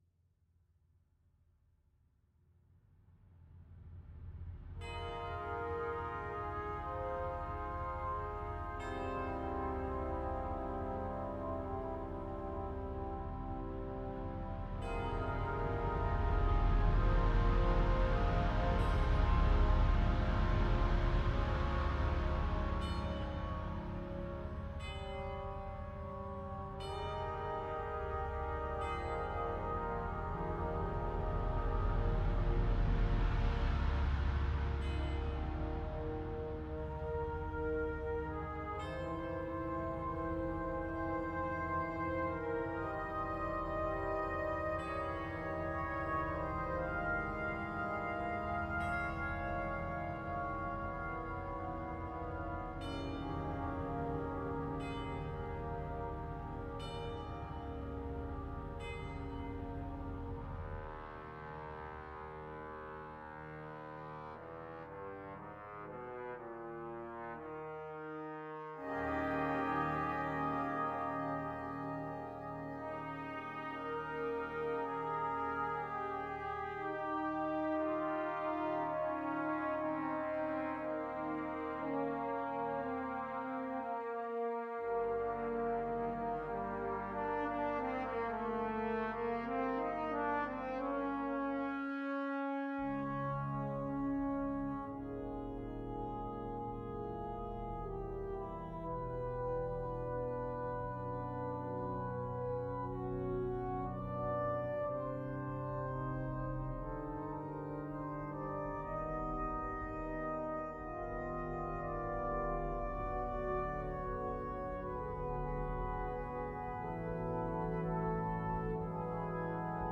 Besetzung: Brass Band